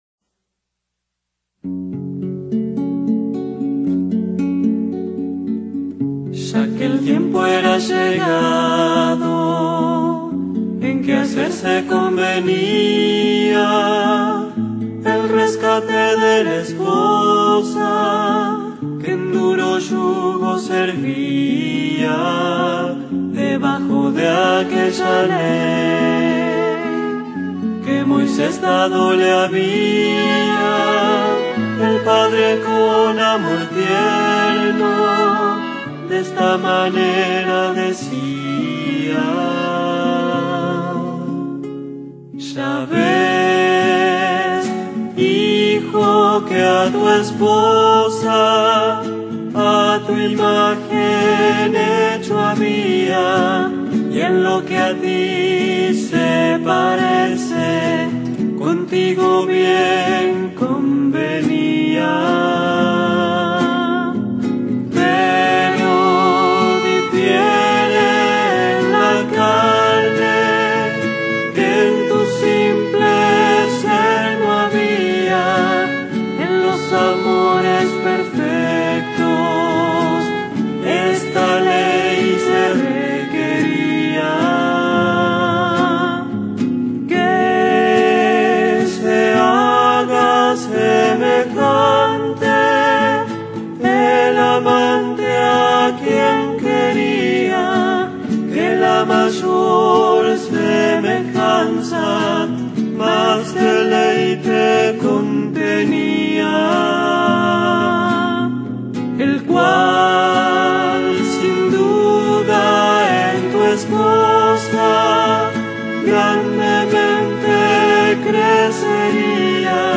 interpretado por un grupo argentino